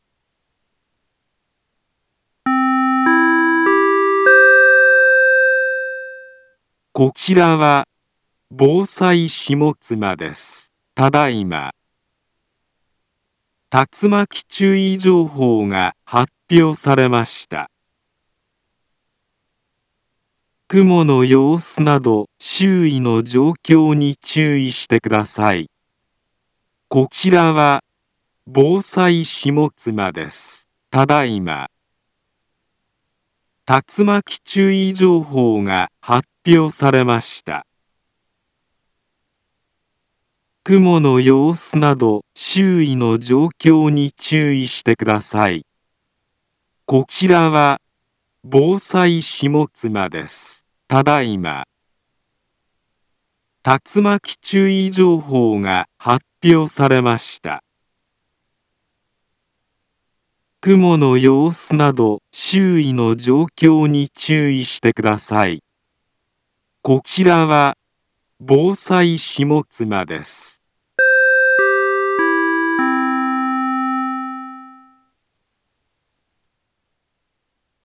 Back Home Ｊアラート情報 音声放送 再生 災害情報 カテゴリ：J-ALERT 登録日時：2025-09-18 13:08:26 インフォメーション：茨城県北部、南部は、竜巻などの激しい突風が発生しやすい気象状況になっています。